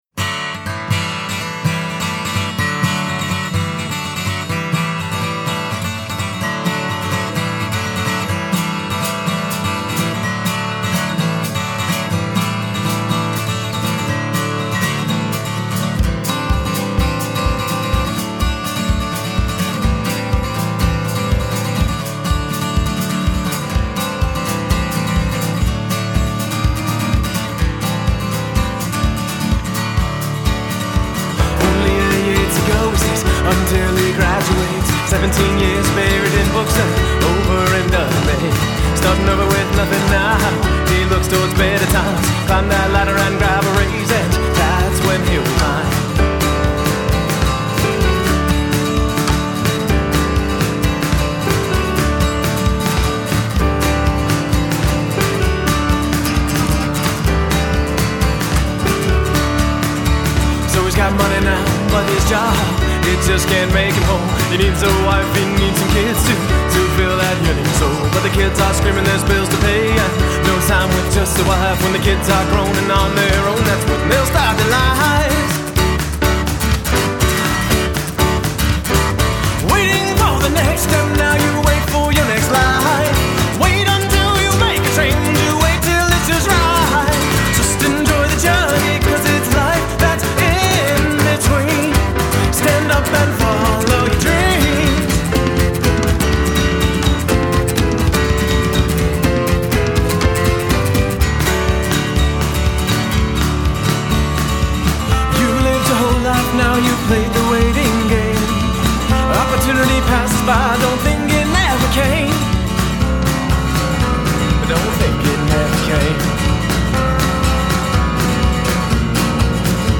Guitar, Vocals
Drums
Piano
Upright Bass